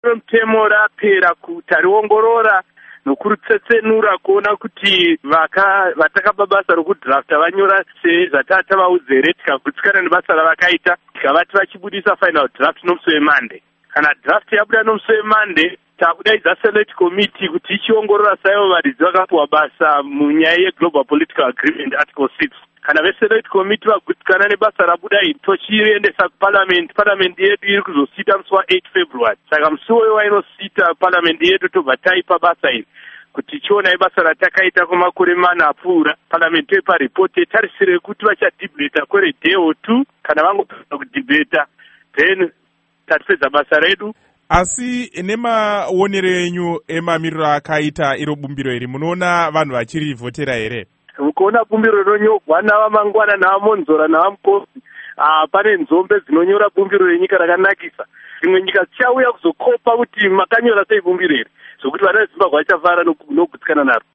Hurukuro naVaMunyaradzi Paul Mangwana